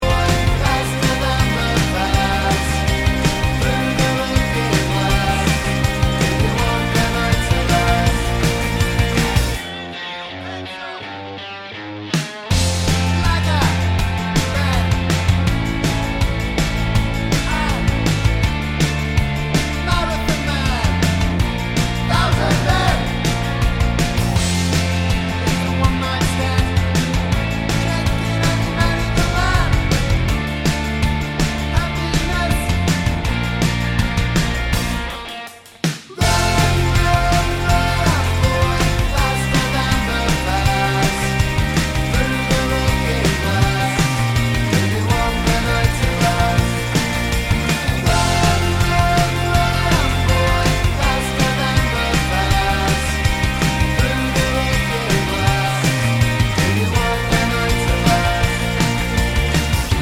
no Backing Vocals Indie / Alternative 2:53 Buy £1.50